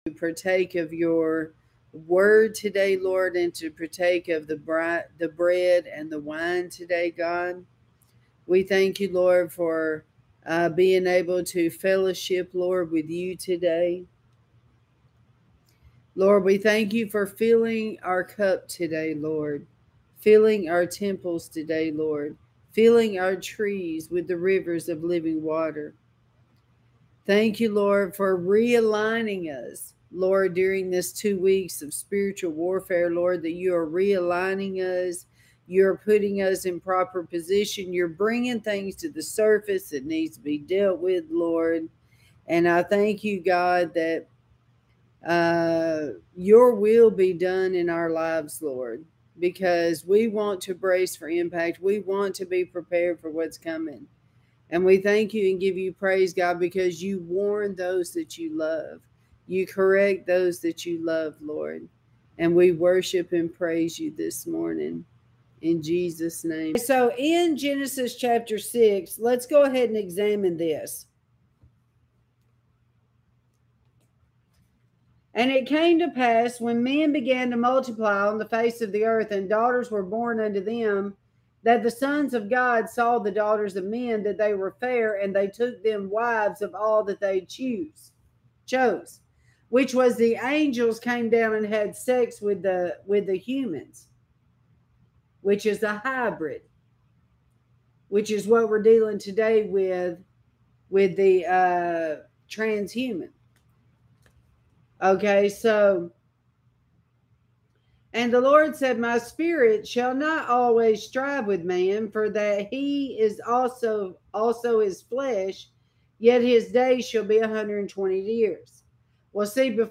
This is a sermon I preached back in 2021 and very much needed today!